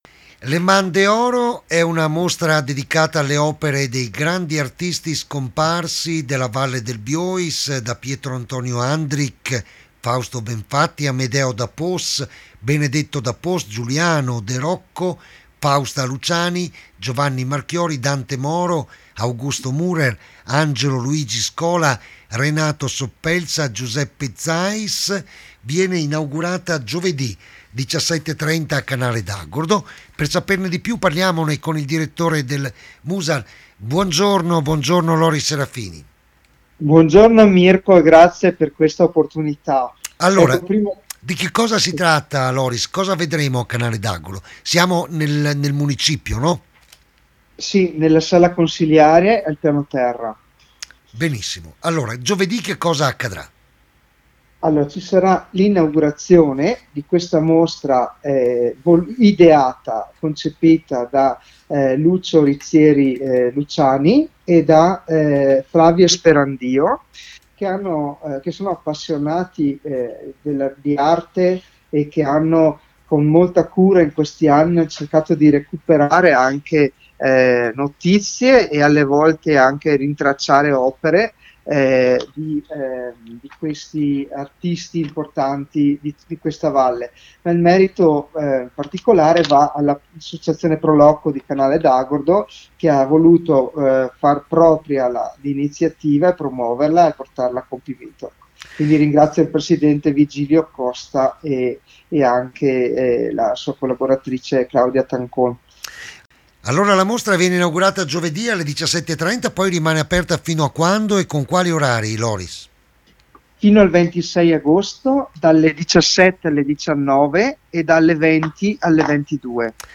L’INTERVISTA ALLA RADIO